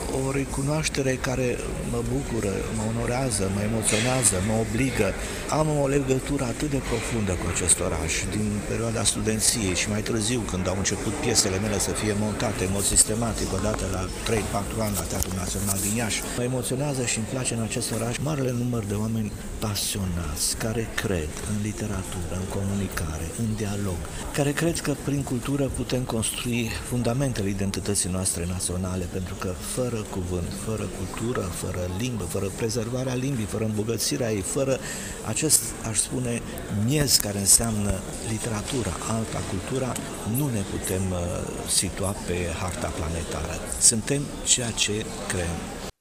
În discursul susținut cu ocazia acordării distincției, scriitorul a subliniat faptul că doar prin cultură ne vom putea menține vie identitatea națională: ”O recunoaștere care mă bucură, mă onorează, mă emoționează, mă obligă.